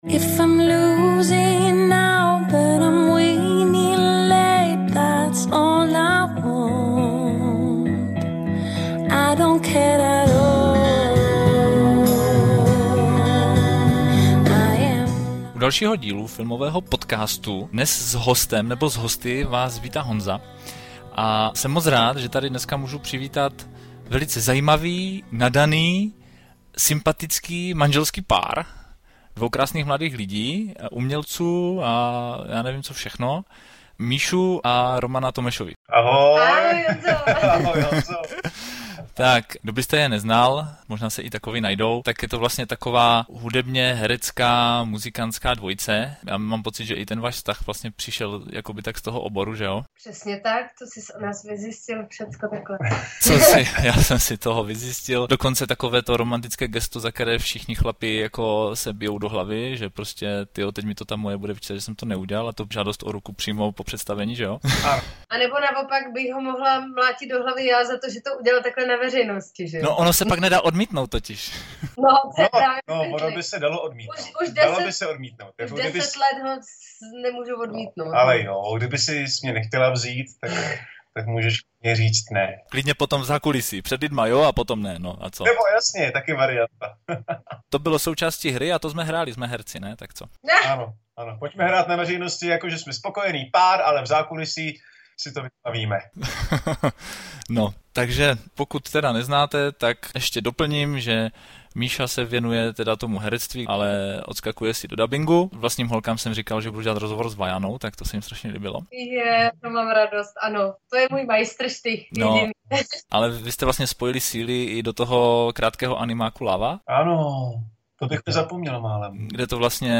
Z nahraných cca 120 minut, je zde těch 83 nejlepších.